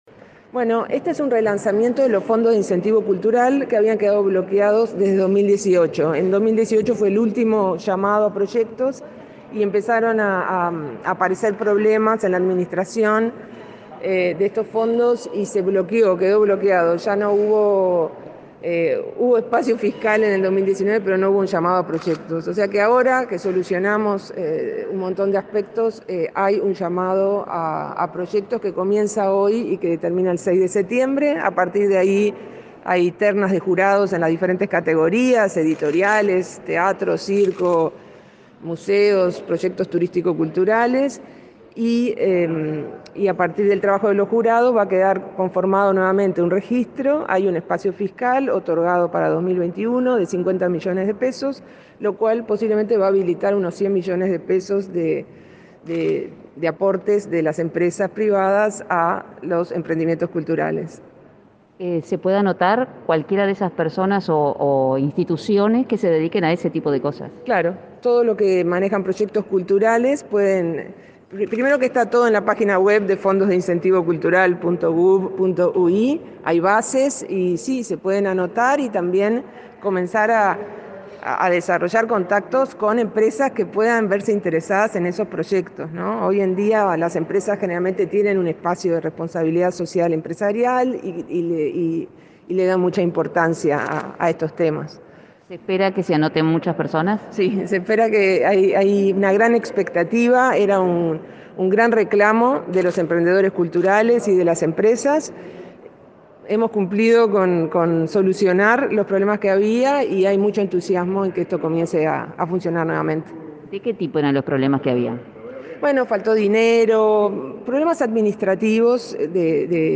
Declaraciones de la directora nacional de Cultura, Mariana Wainstein